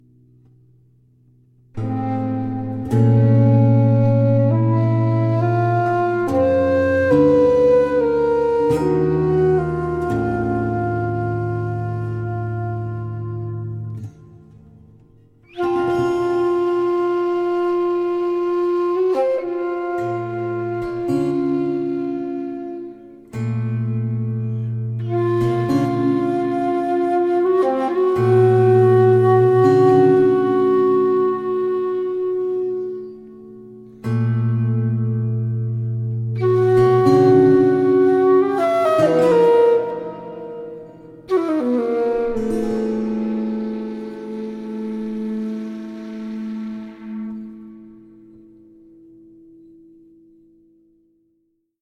Tenor and Soprano saxophones, Alto flute, Bansuri flute